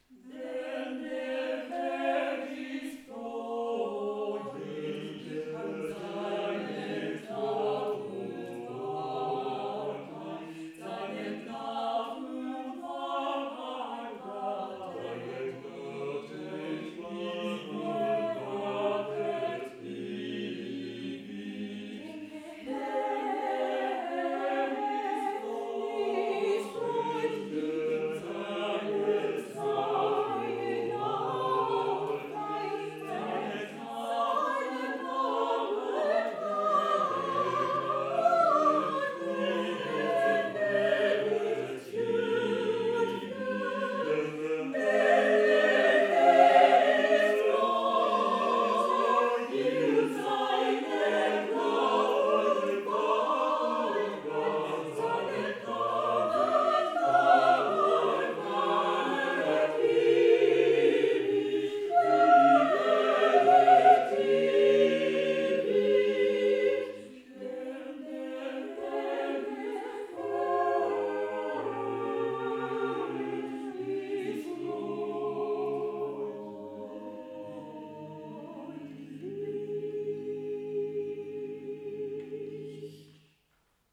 2013年11月30日、大泉学園ゆめりあホールで、合唱を録音
ステージと客席の間にセッティング。
高さはステージとほぼ同じ高さです。
AT9943は、ビデオカメラでリニアPCM48kHz16bitで録音
大泉学園ゆめりあホール